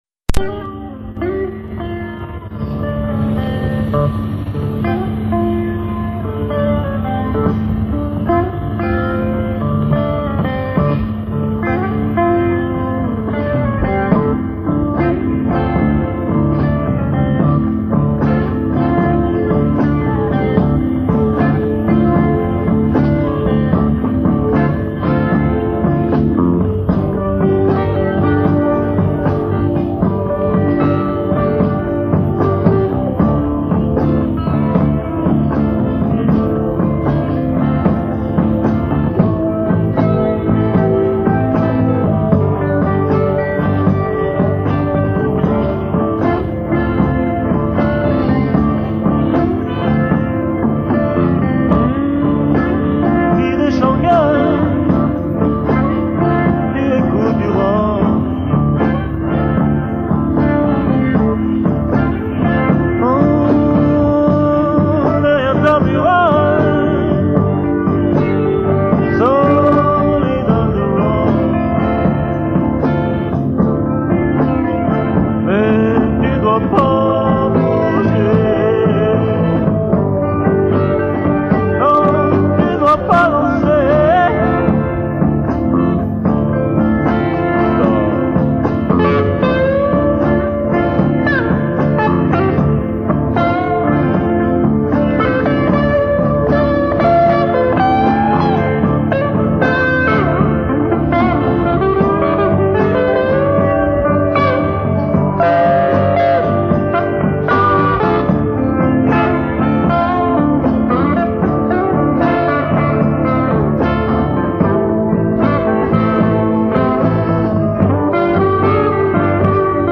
Influences très jazz et blues pour ces jeunes musiciens bourrés de talent.
2 saxophonistes : le grand luxe !! malheureusement, 3 d'entre eux sont partis
Un son très mauvais, du fait d'une succession d' "overdub" avec un magnéto K7 mono.
La qualité sonore est inférieure à l'original du fait de la compression sévère  ( ! ) mais reste acceptable